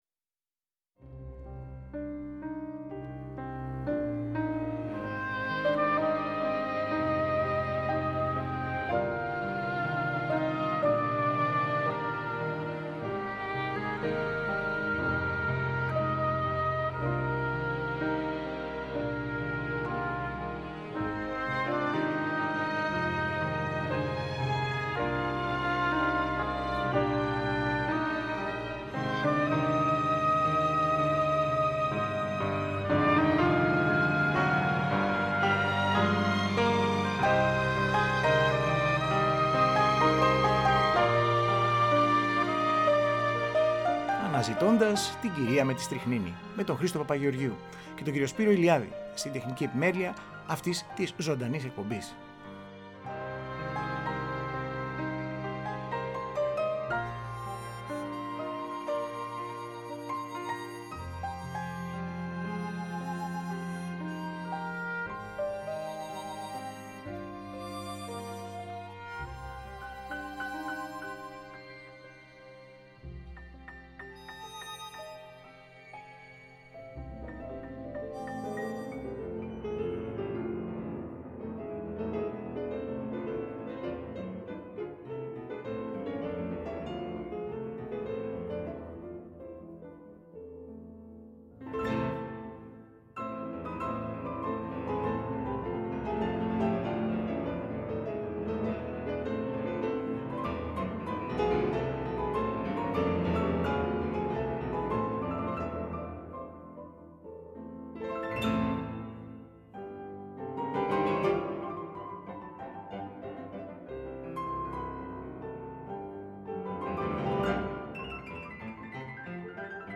Συγκριτικές ερμηνείες του διασημότερου έργου του Johannes Brahms.